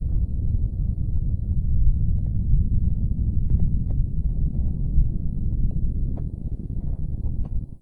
env_sounds_lava.1.ogg